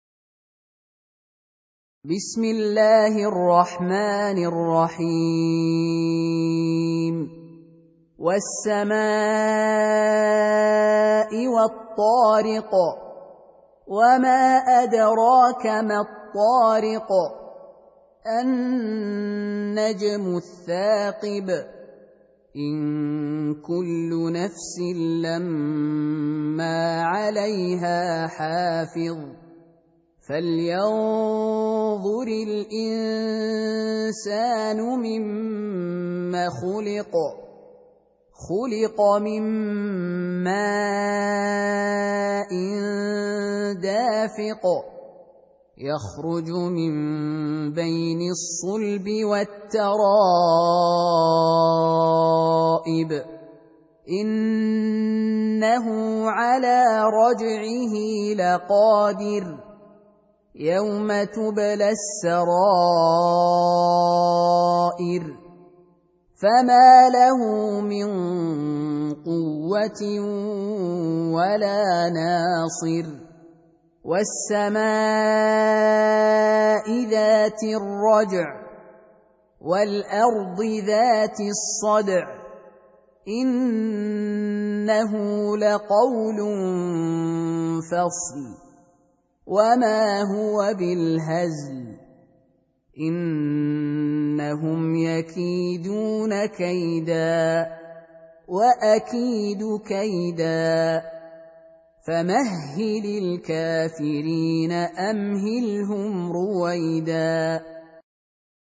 Surah Sequence تتابع السورة Download Surah حمّل السورة Reciting Murattalah Audio for 86. Surah At-T�riq سورة الطارق N.B *Surah Includes Al-Basmalah Reciters Sequents تتابع التلاوات Reciters Repeats تكرار التلاوات